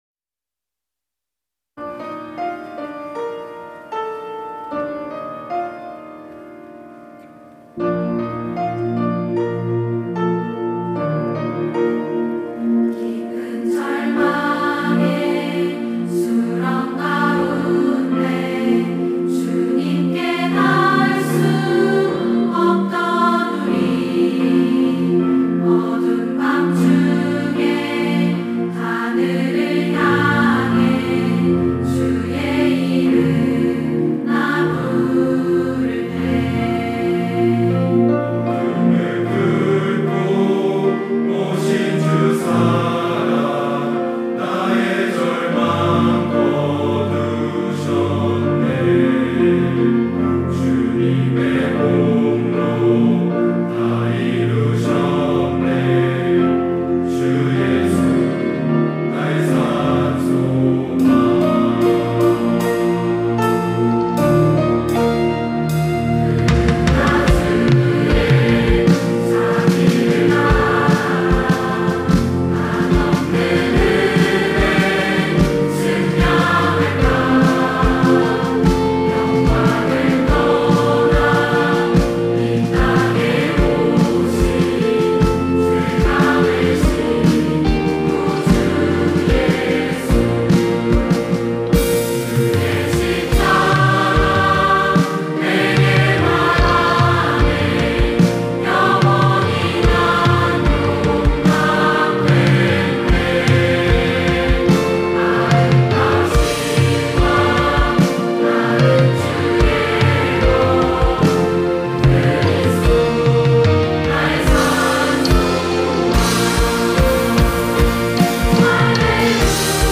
특송과 특주 - 주 예수 나의 산 소망
청년부 찬양대